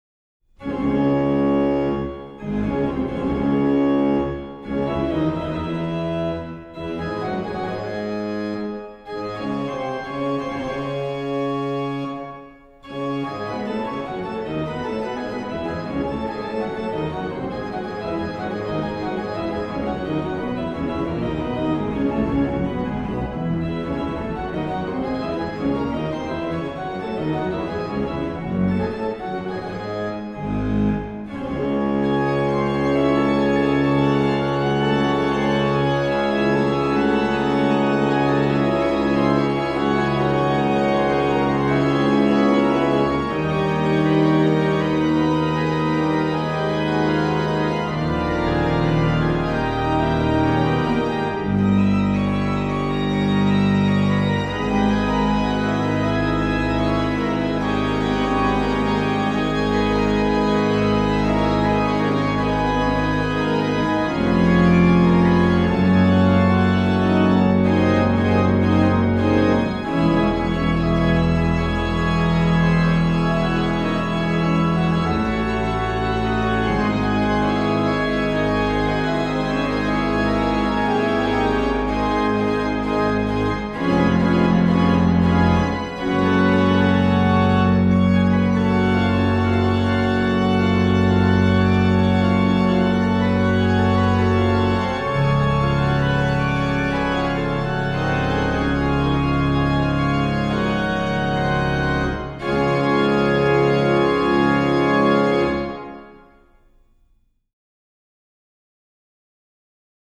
MAN: Qnt16, Pr8, Oct4, Qnt3, Oct2, POS/MAN
POS: Pr4, Oct2, Mix
PED: Viol16, Oct8, Pos16, MAN/PED, POS/PED